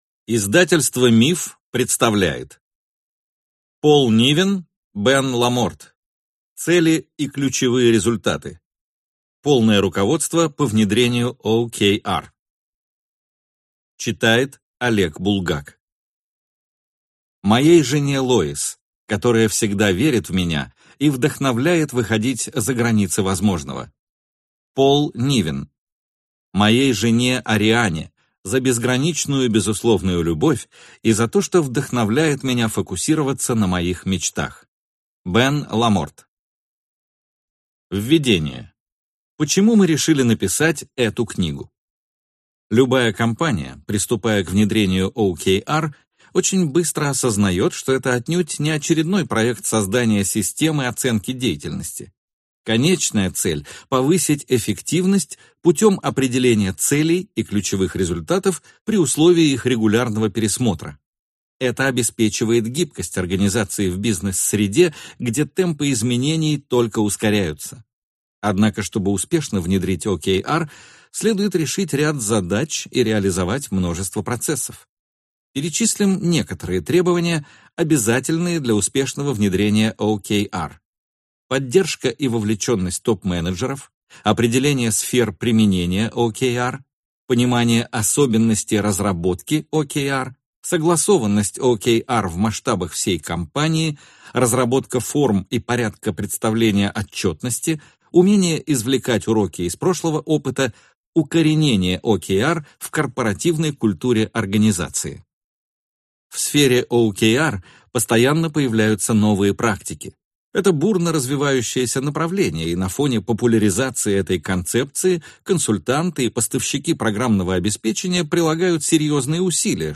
Аудиокнига Цели и ключевые результаты. Полное руководство по внедрению OKR | Библиотека аудиокниг